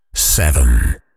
Audio voiceover and fun !